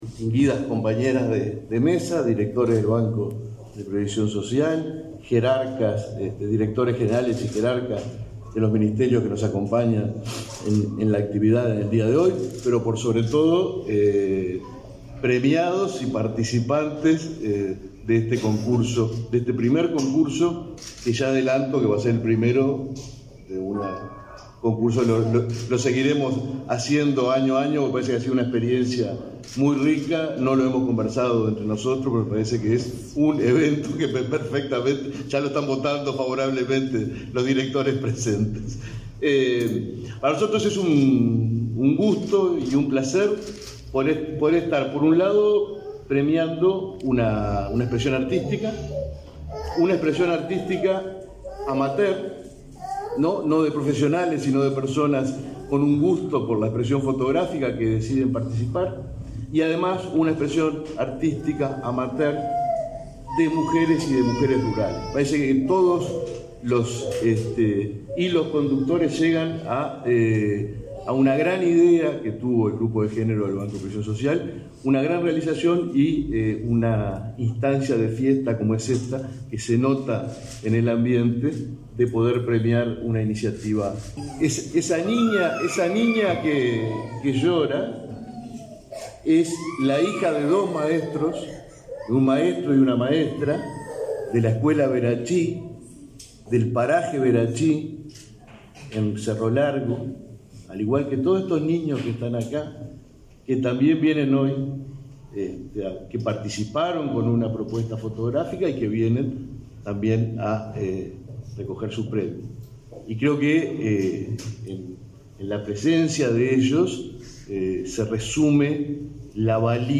Conferencia de prensa por el Día Internacional de las Mujeres Rurales
Participaron en el evento la directora de Instituto Nacional de las Mujeres, Mónica Bottero; el presidente del Banco de Previsión Social (BPS), Alfredo Cabrera, y la directora del BPS Daniela Barindelli.